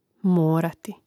mórati morati